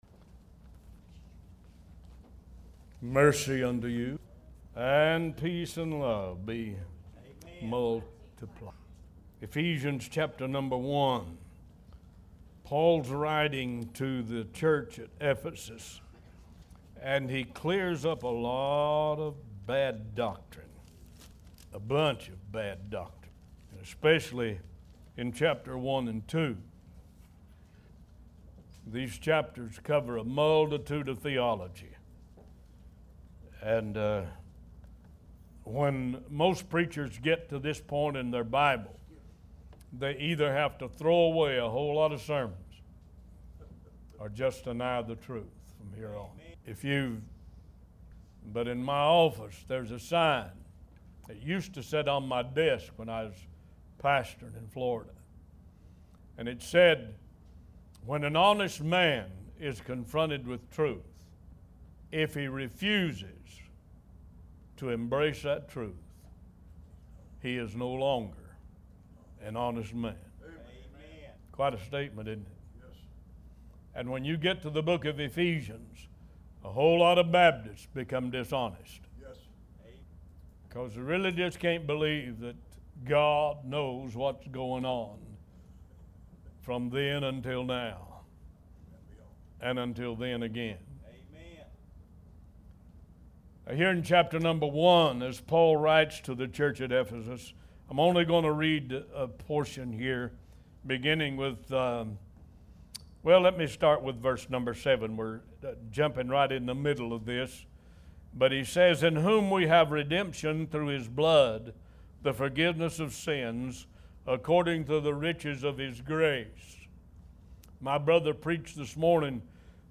New Sermons published every Sunday and Wednesday at 11:30 AM EST